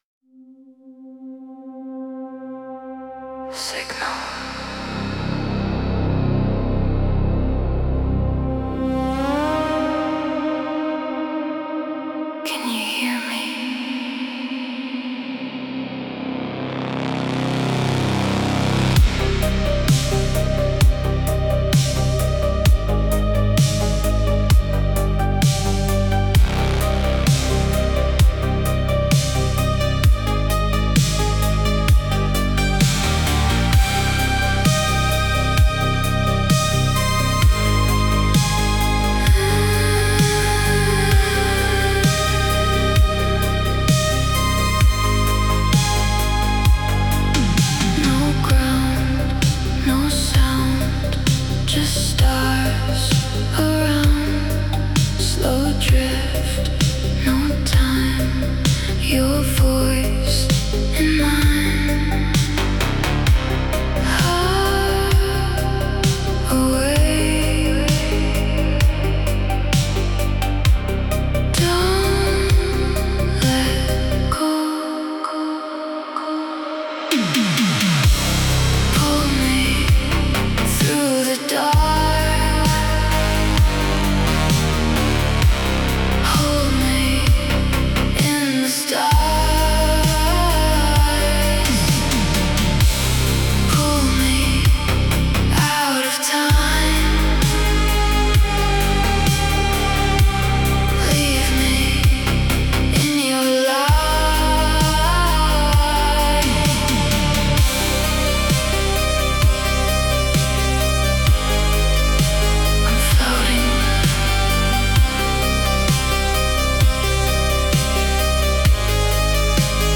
Electronic music
Style: Synthwave